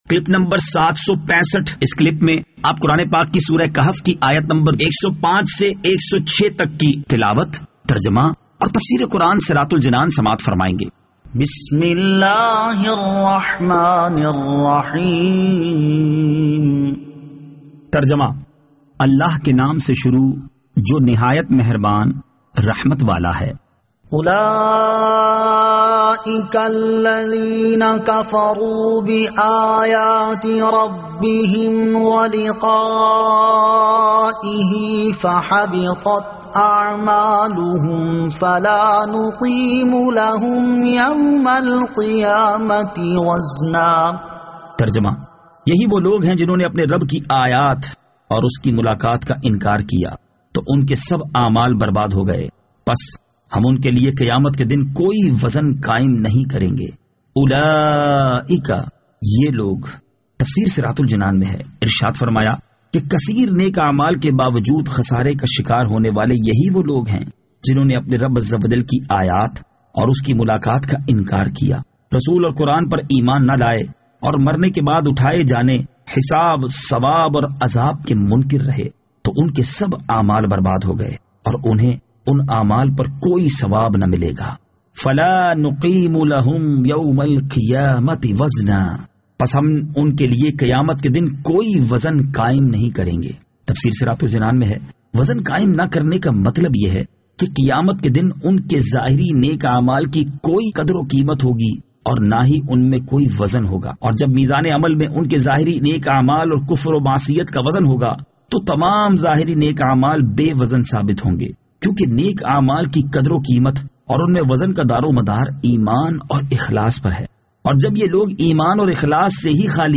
Surah Al-Kahf Ayat 105 To 106 Tilawat , Tarjama , Tafseer
2021 MP3 MP4 MP4 Share سُوَّرۃُ الکَھْفِ آیت 105 تا 106 تلاوت ، ترجمہ ، تفسیر ۔